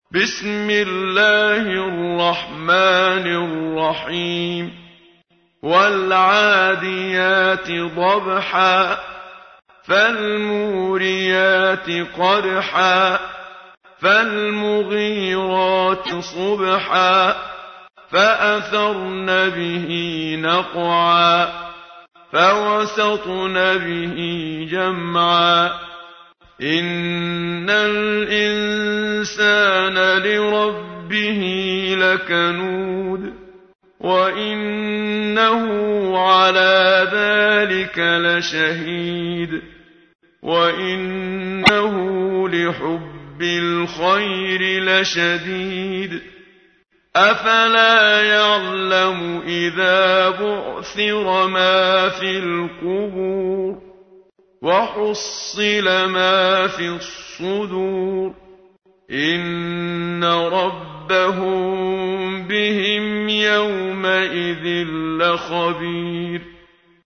تحميل : 100. سورة العاديات / القارئ محمد صديق المنشاوي / القرآن الكريم / موقع يا حسين